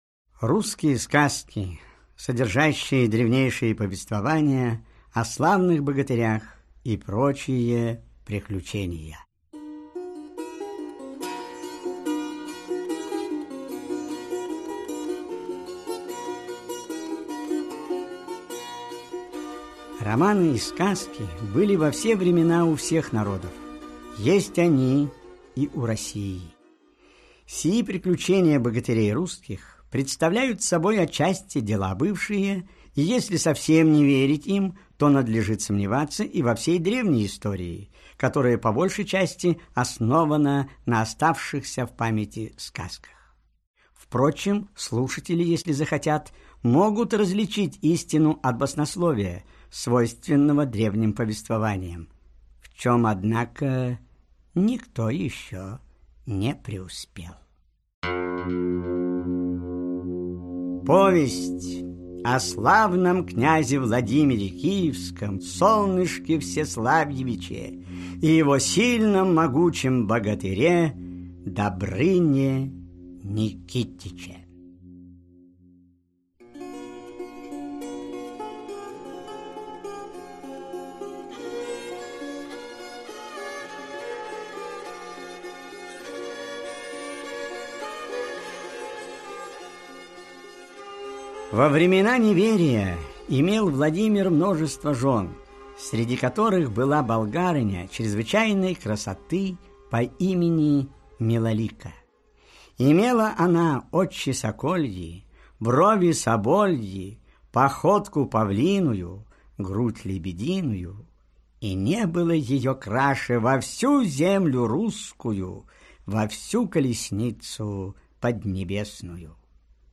Аудиокнига Легенды о славянских витязях | Библиотека аудиокниг
Aудиокнига Легенды о славянских витязях Автор Народное творчество Читает аудиокнигу Валерий Золотухин.